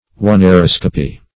Search Result for " oneiroscopy" : The Collaborative International Dictionary of English v.0.48: Oneiroscopy \O`nei*ros"co*py\, n. [Gr.